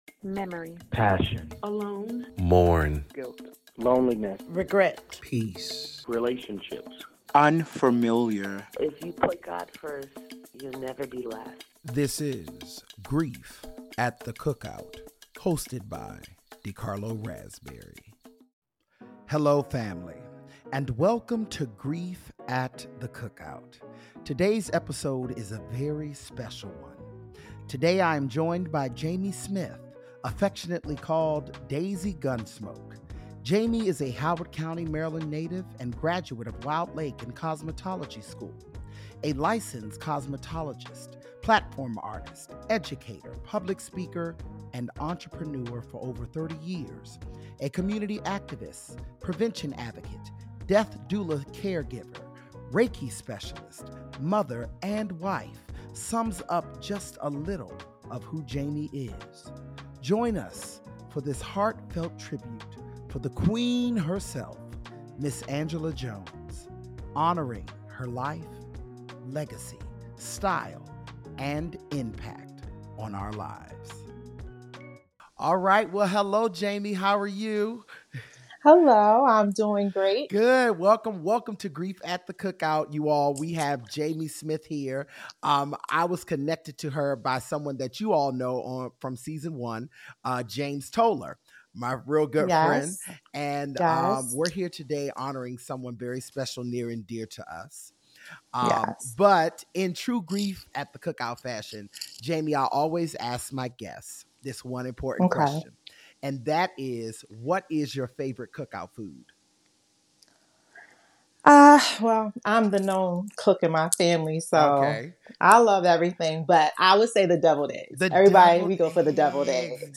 Real, Raw, Honest Conversation...